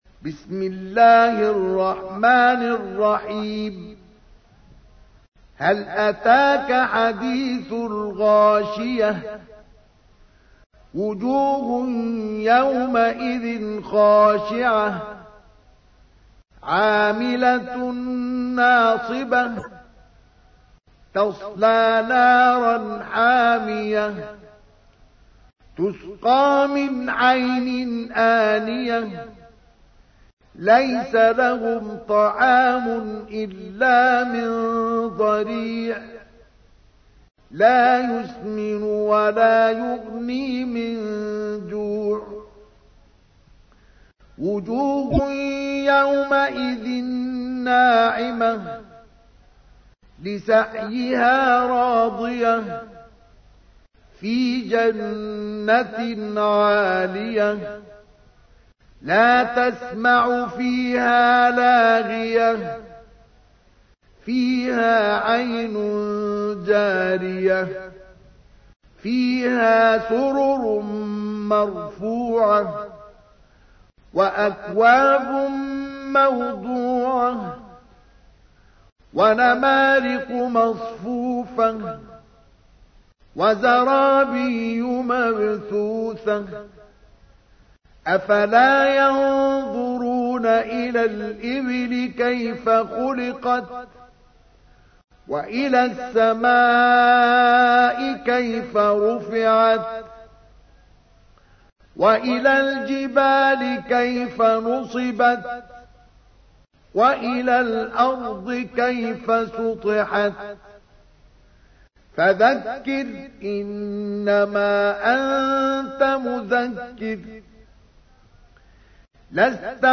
تحميل : 88. سورة الغاشية / القارئ مصطفى اسماعيل / القرآن الكريم / موقع يا حسين